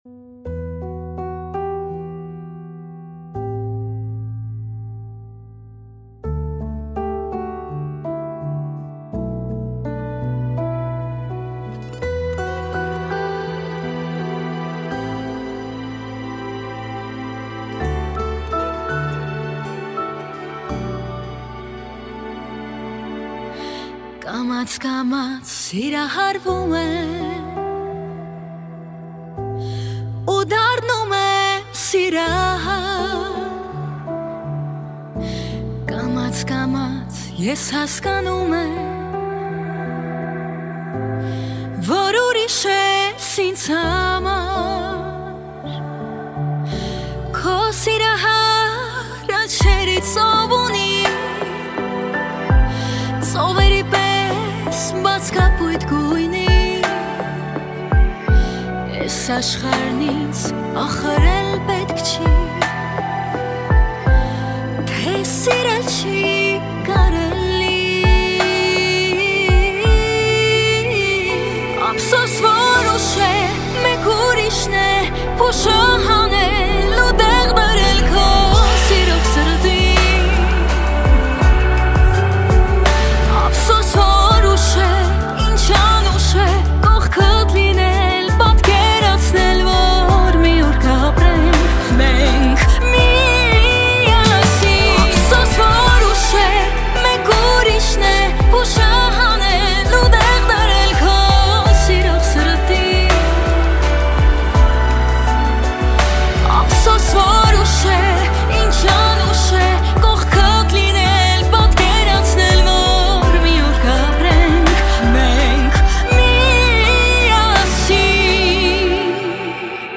Музыка » Армянская музыка